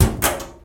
Polaris/sound/effects/locker_close.ogg
locker_close.ogg